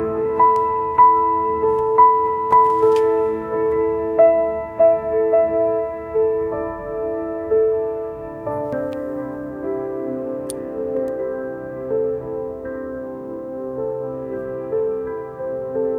ambient-soothing-piano-wi-c2ebmqt7.wav